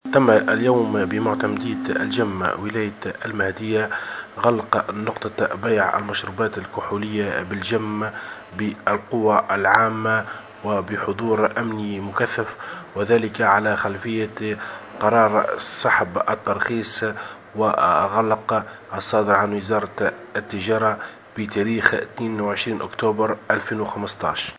مراسلنا في المهدية